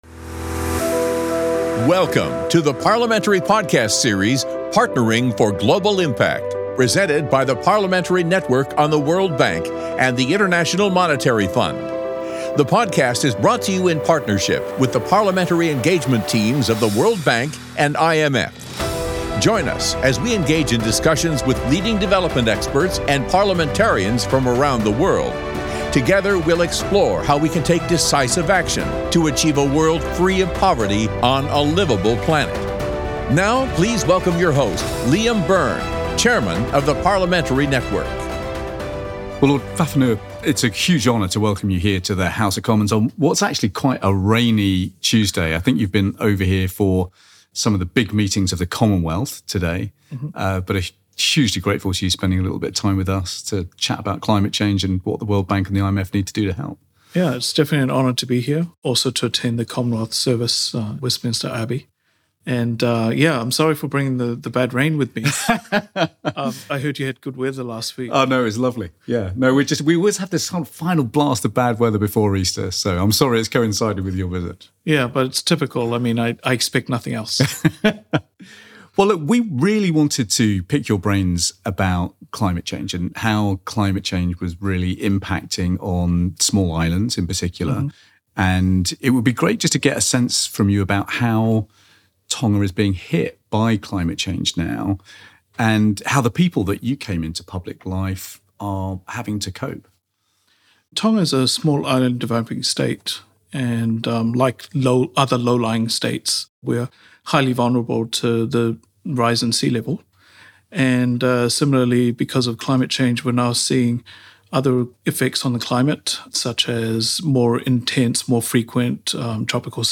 Parliamentary Network on the World Bank and IMF Chair Liam Byrne interviews Lord Fakafanua in London, UK.